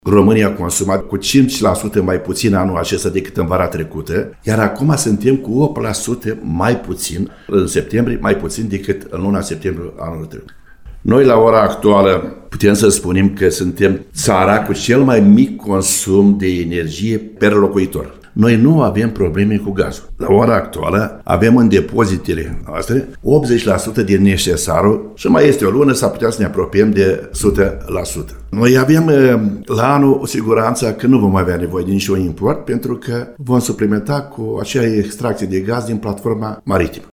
El a declarat postului nostru că măsurile luate în cadrul coaliției “vor conduce la rezolvarea crizei energiei”.